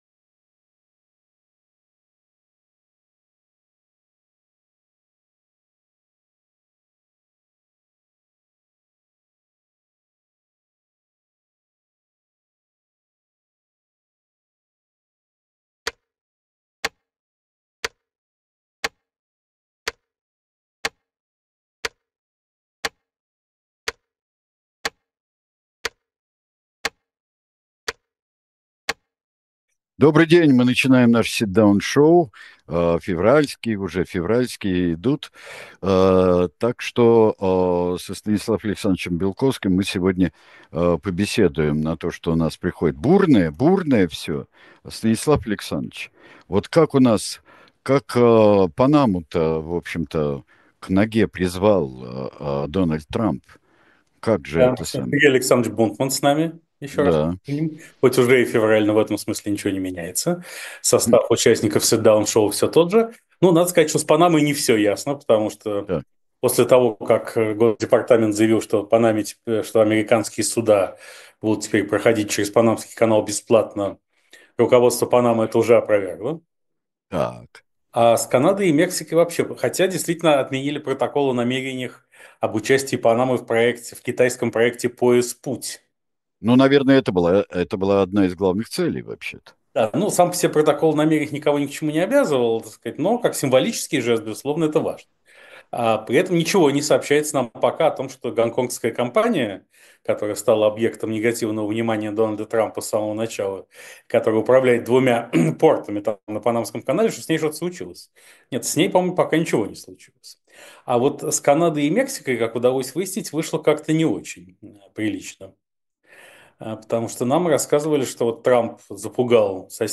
Программу ведет Сергей Бунтман.